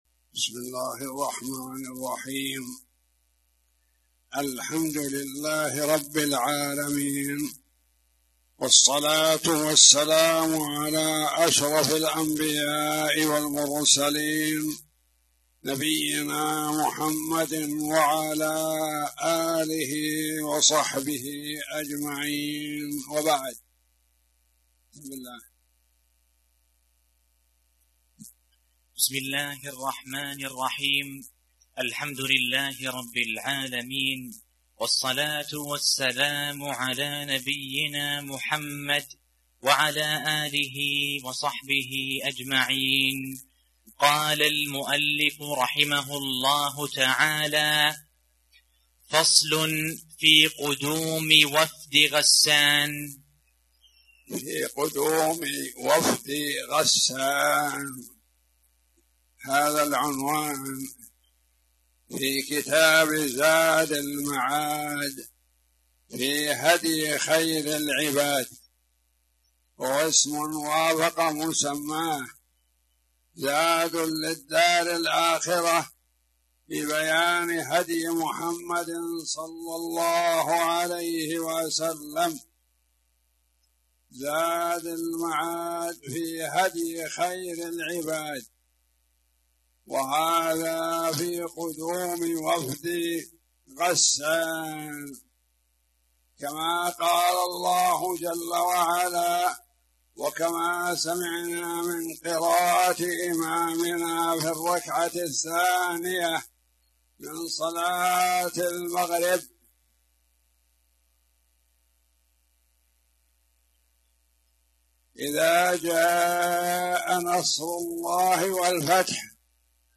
تاريخ النشر ١٣ شوال ١٤٣٨ هـ المكان: المسجد الحرام الشيخ